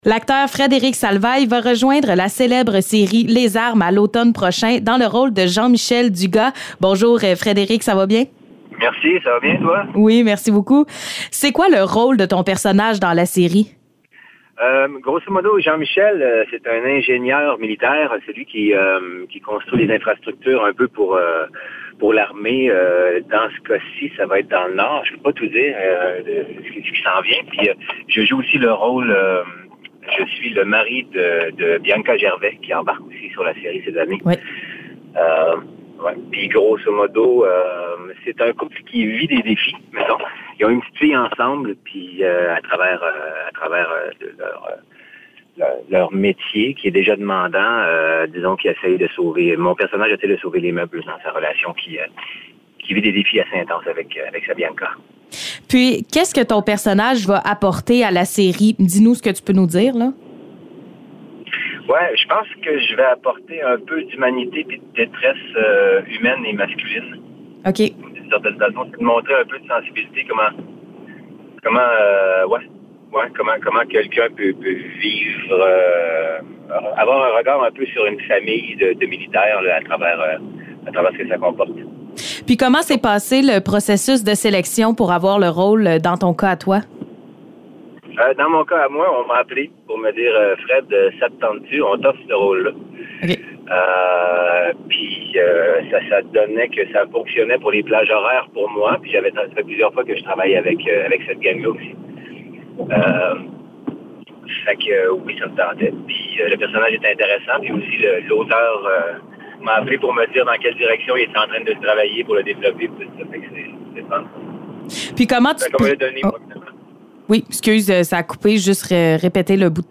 Entrevue avec Fred-Éric Salvail